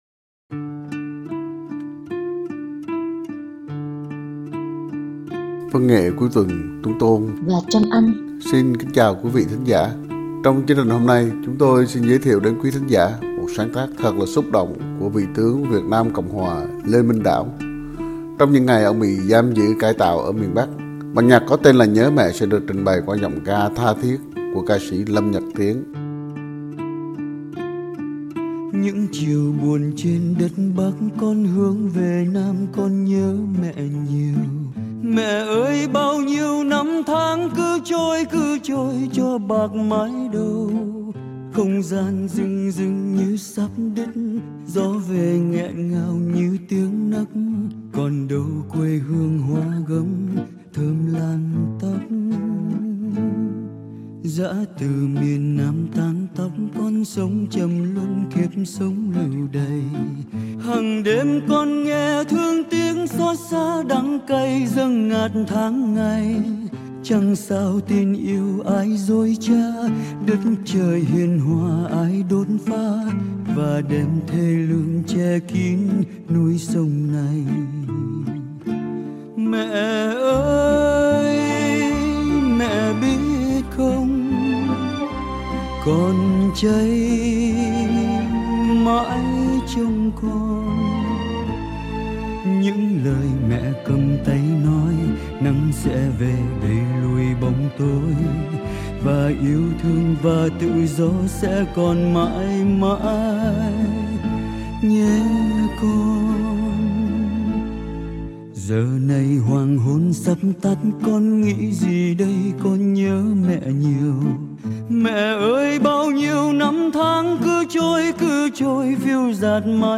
Các bản nhạc viết về Mẹ hoặc viết cho Mẹ thường chứa đựng một cảm xúc rất thiêng liêng đặc biệt và gần như sáng tác nào cũng có giai điệu ngọt ngào, du dương cả.